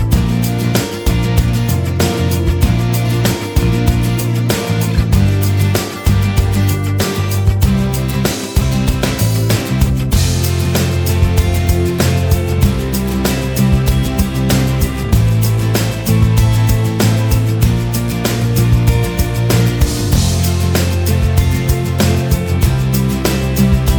Minus Main Guitars Pop (1990s) 3:30 Buy £1.50